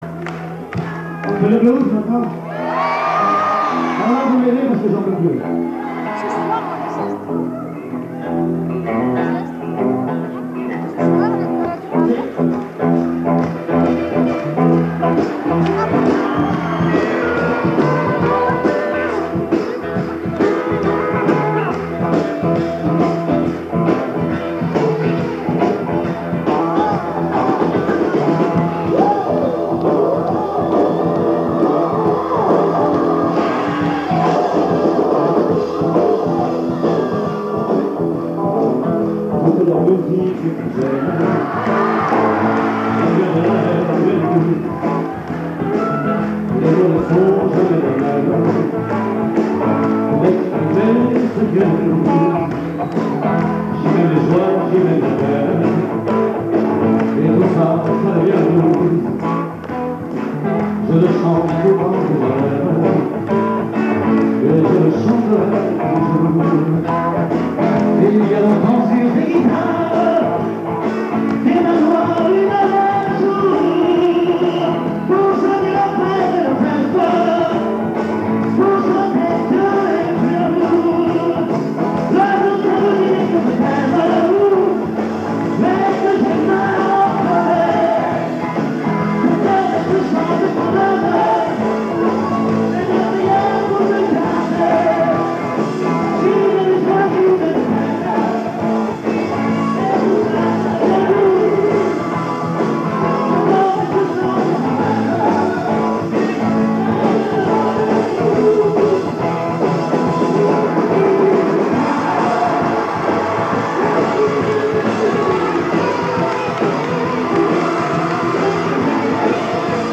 Bootlegs (enregistrements en salle)